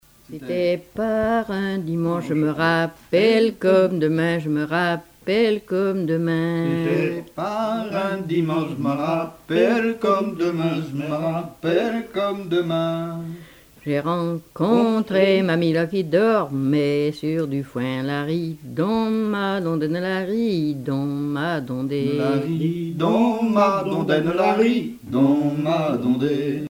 Genre laisse
Chansons traditionnelles